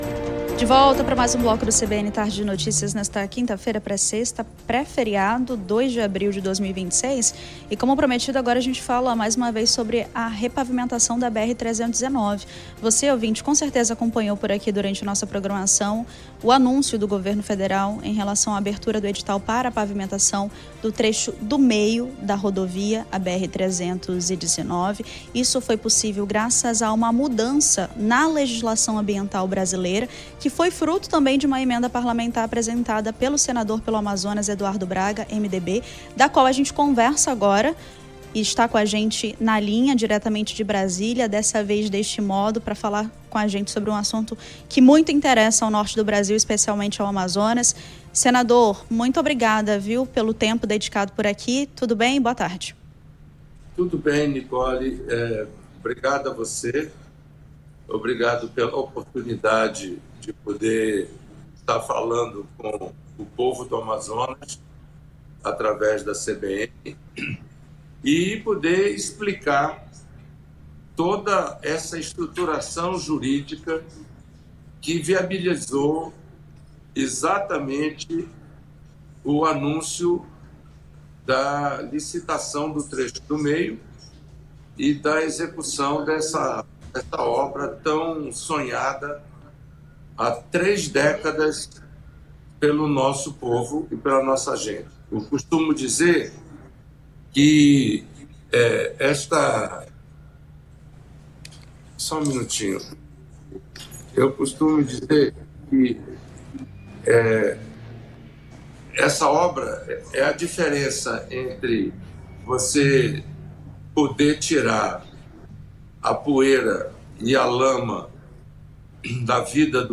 Senador Eduardo Braga fala sobre pavimentação da BR-319 em entrevista à CBN Amazônia
ENTREVISTA Manaus Senador Eduardo Braga fala sobre pavimentação da BR-319 em entrevista à CBN Amazônia A entrevista foi concedida ao programa CBN Tarde de Notícias